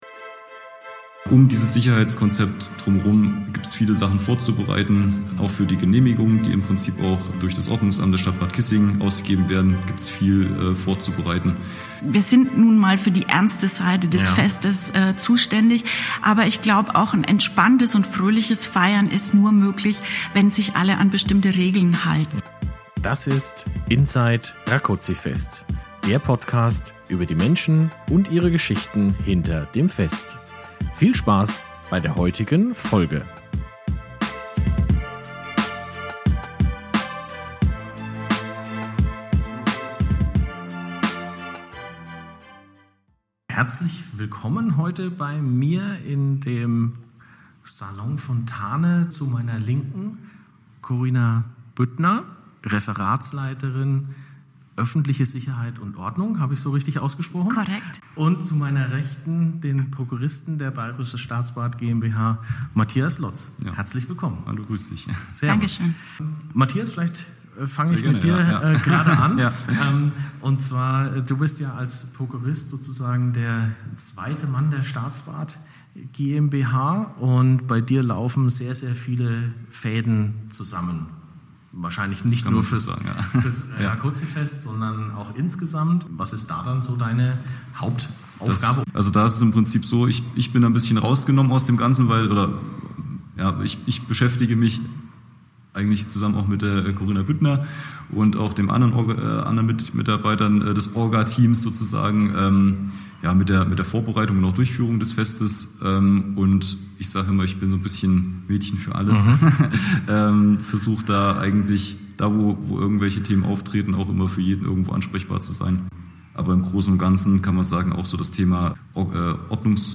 Beschreibung vor 1 Jahr Es geht in dieser Folge darum, was alles getan werden muss, damit der Festablauf reibungslos und sicher verläuft. Wer genehmigt denn eigentlich die einzelnen Attraktionen am Fest und wer überwacht und überprüft dann auch deren Einhaltung? Heute sind im Gespräch